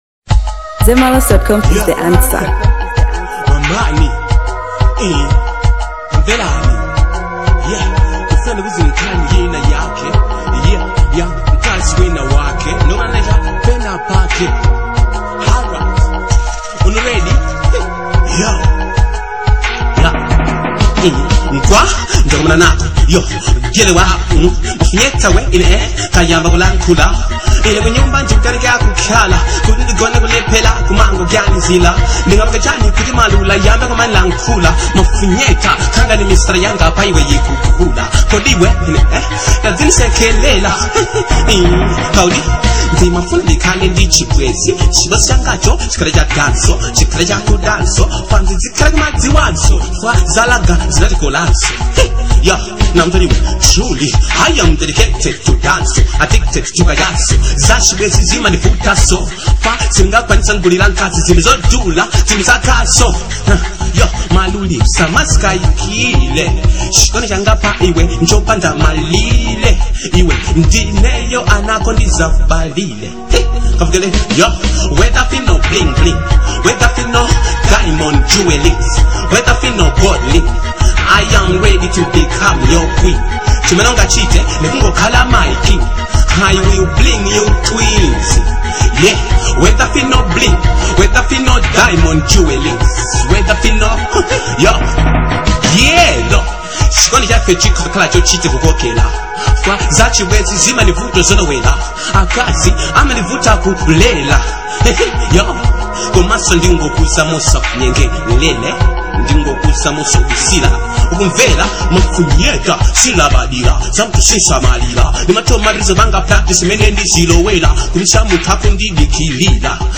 Dancehall • 2025-09-15